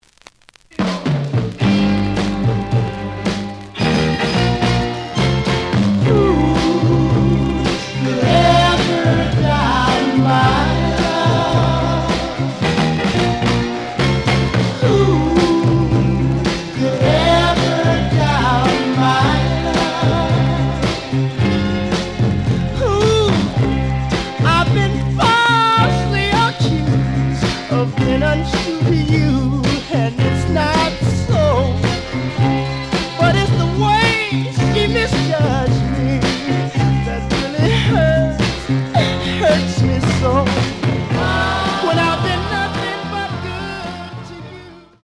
試聴は実際のレコードから録音しています。
●Genre: Soul, 60's Soul